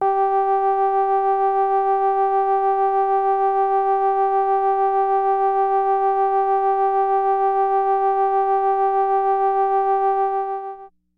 标签： FSharp4 MIDI音符-67 雅马哈-CS-30L 合成器 单票据 多重采样
声道立体声